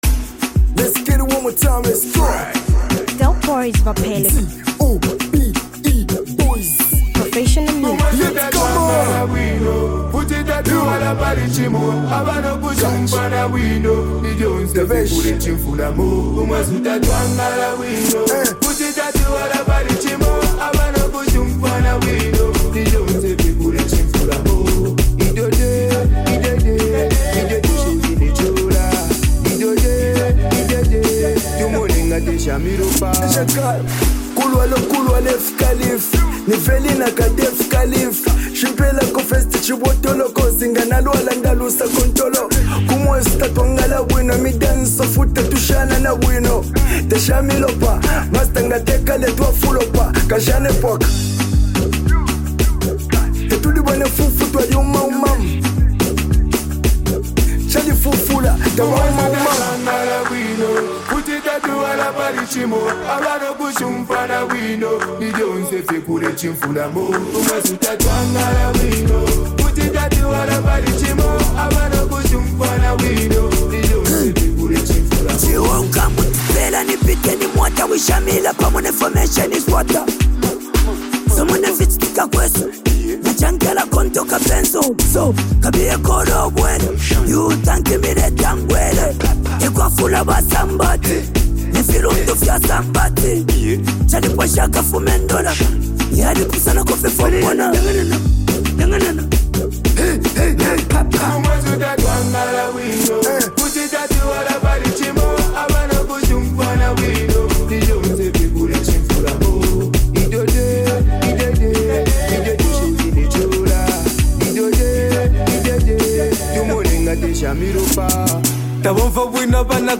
hard-hitting Zambian street anthem